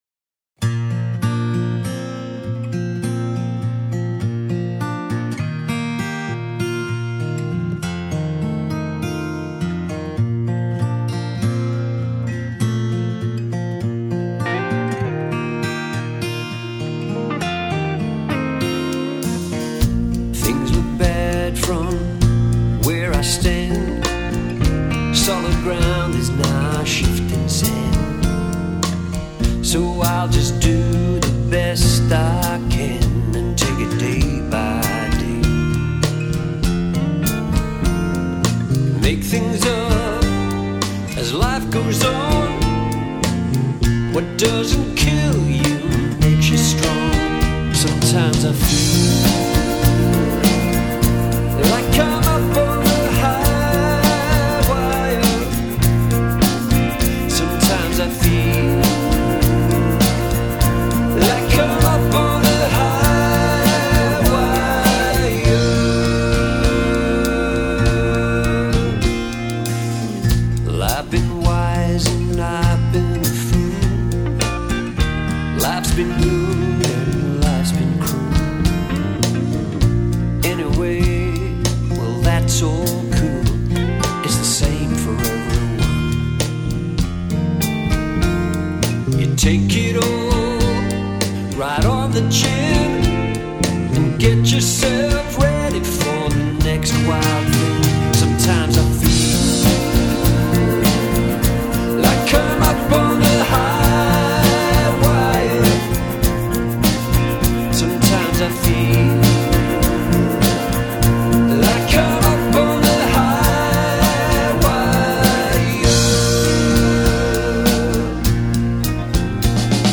ethereal new song
the enchanting prayer of peace and well-being.”